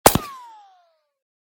whine_9.ogg